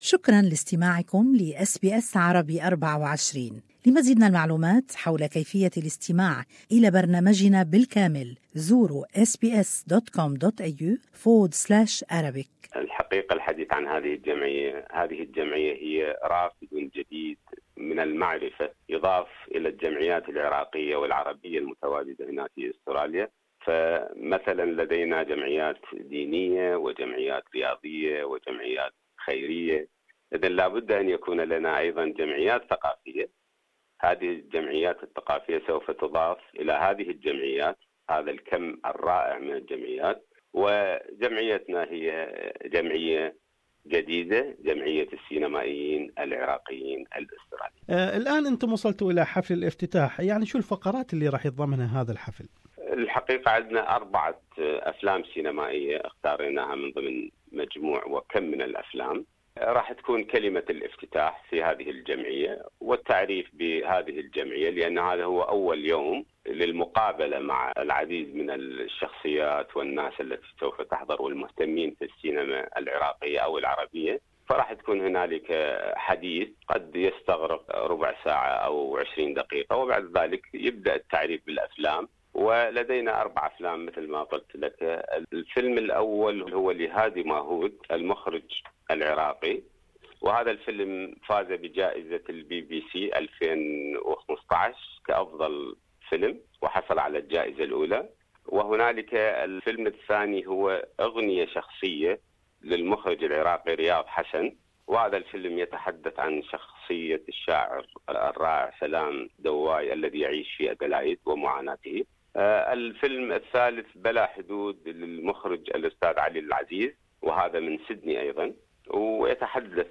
A new Iraqi Australian filmmakers association will kick off in Melbourne on 18 May. The opening with screen four films for Iraqi directors and actors. More is in this interview